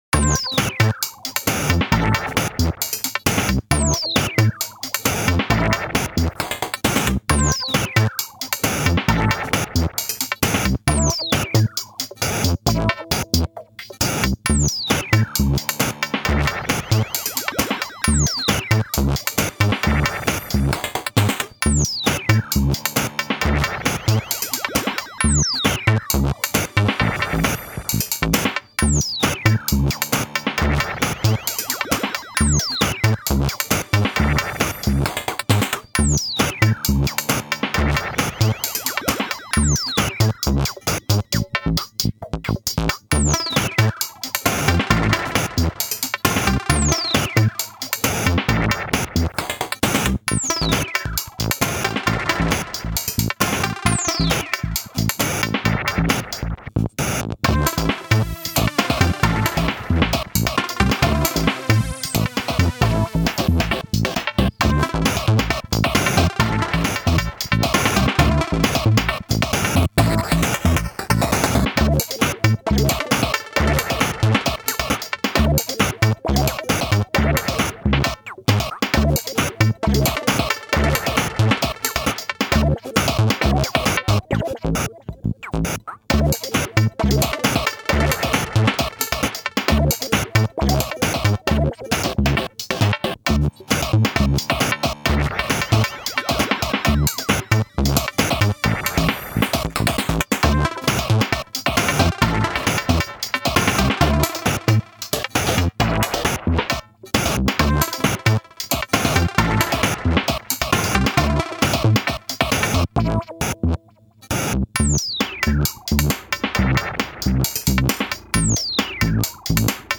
Here's a noisy little ditty that came about when the elektron family got together at my place over the holidays. Uncle Sid does the bass and snare, with cousin SPS and the freaky grandma SFX providing the backing sounds. This is a rough take, and the last minute or so will probably get clipped.